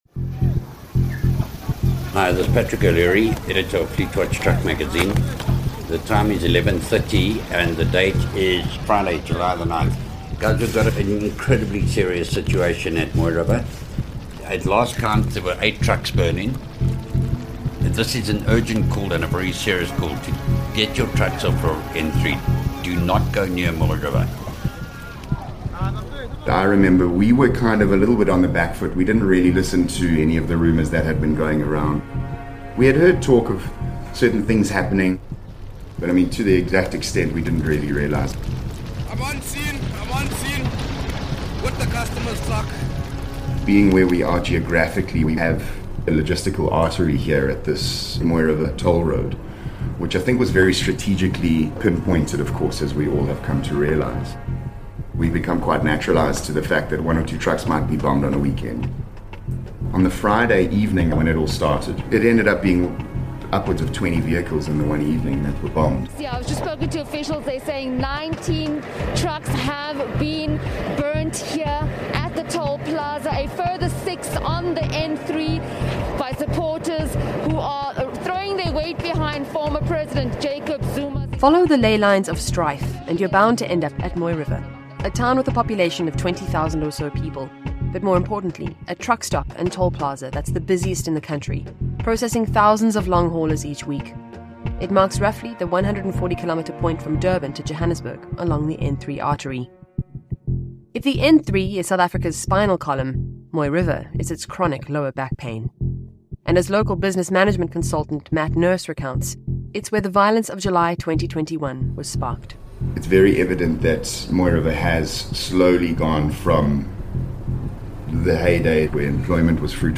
limited audio documentary series